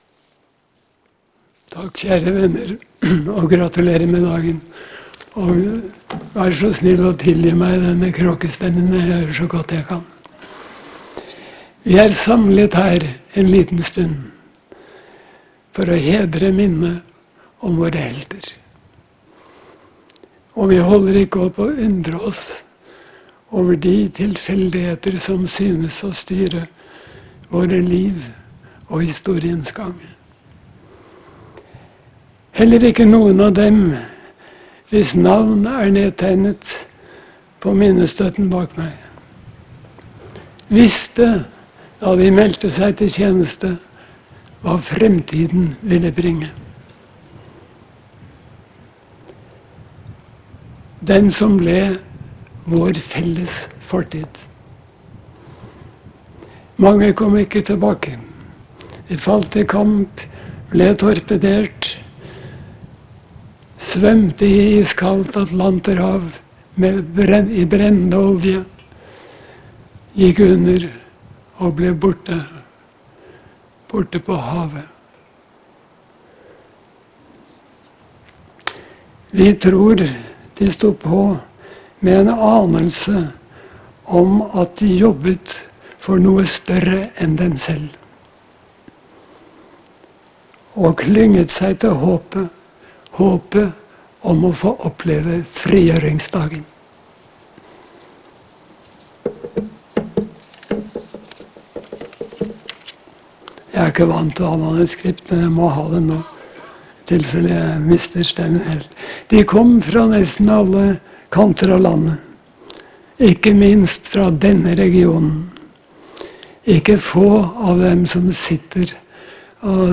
En tale av Kjell Arnljot Wiig
en engasjert  Kjell Arnljot Wiig holde tale i Skudeneshavn.
Kjell Arnljot Viig hadde en rusten stemme , men ordene var kraftfulle.